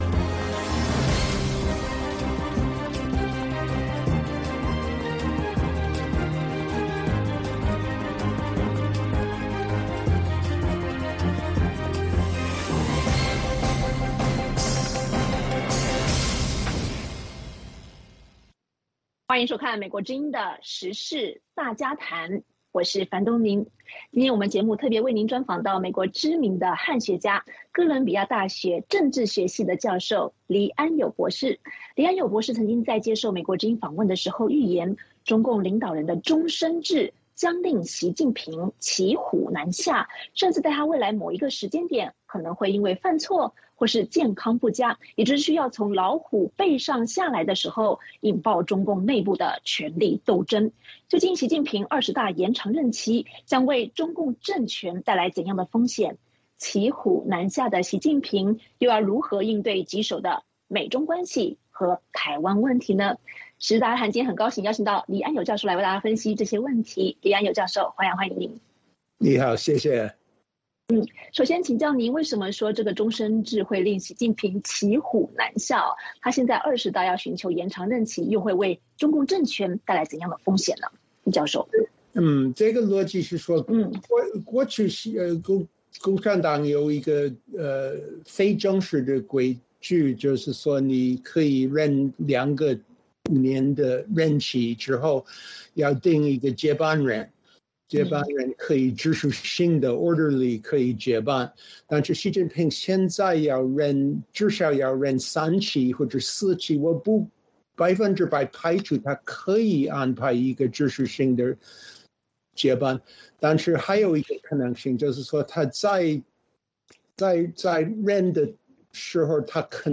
本期时事大家谈专访美国知名汉学家、哥伦比亚大学政治学系教授黎安友。